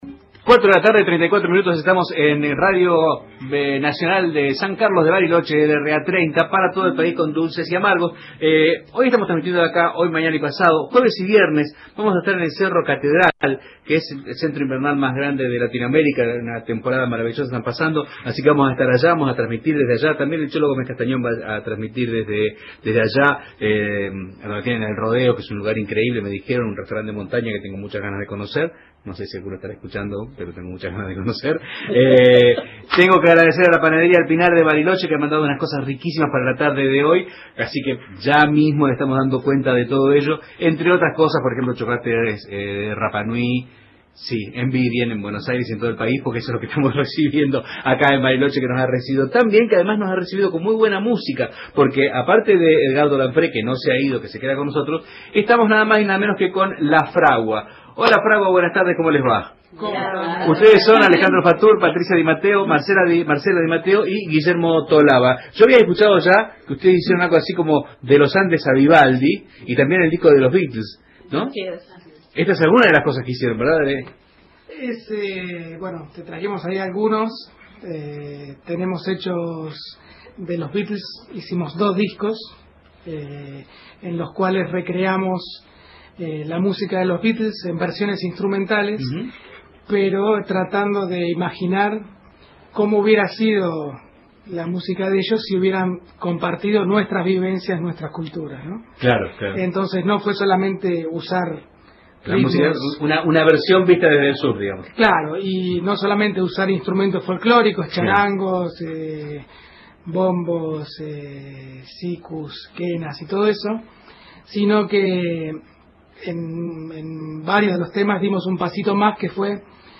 en los estudios de LRA 30 Radio Nacional Bariloche